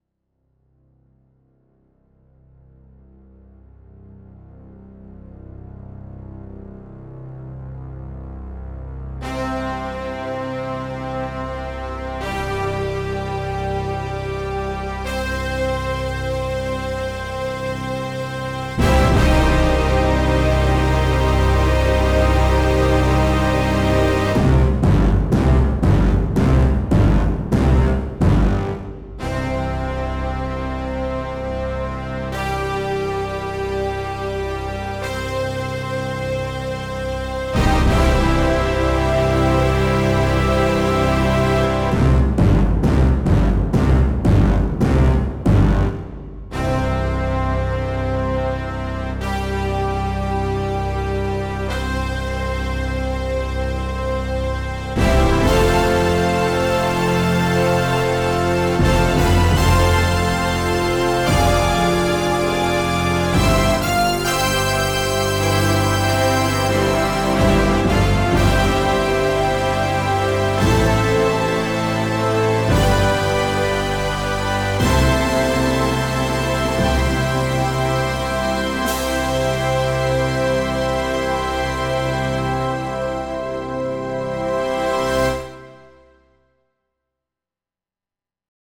lab Korg M 3